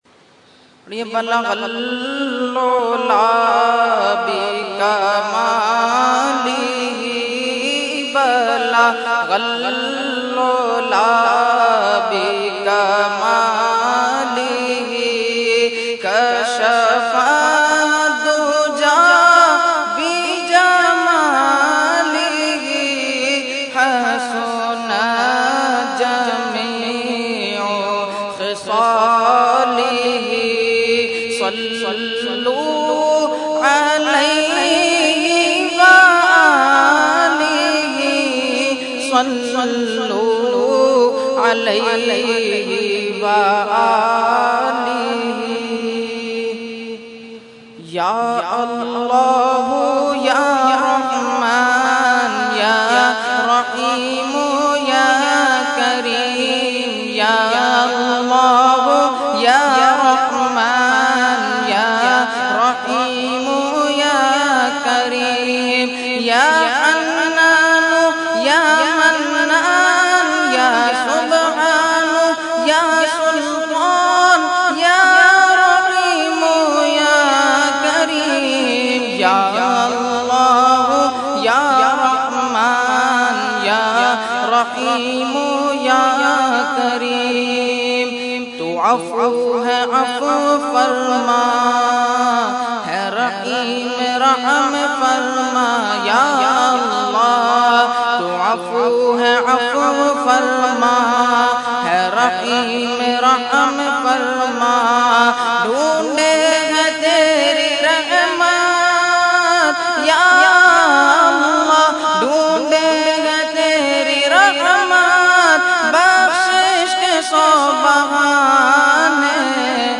Category : Hamd | Language : UrduEvent : Dars Quran Farooqi Masjid 10 August 2012